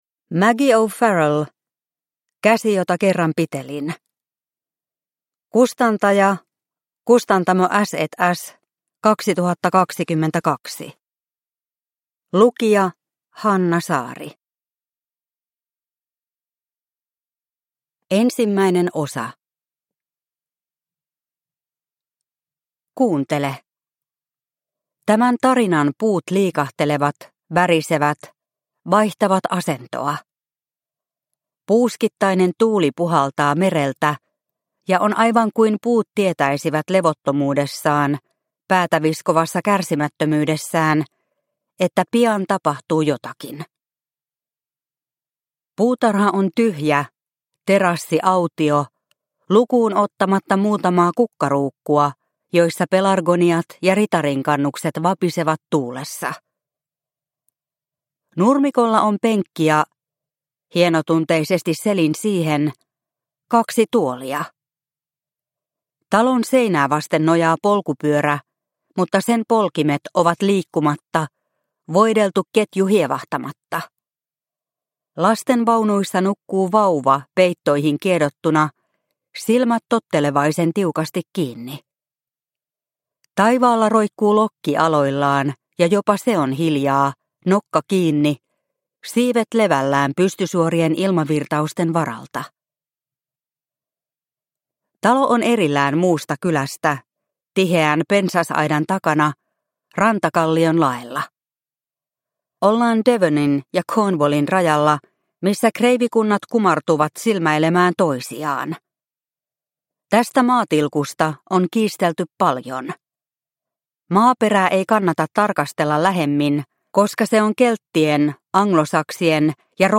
Käsi jota kerran pitelin – Ljudbok – Laddas ner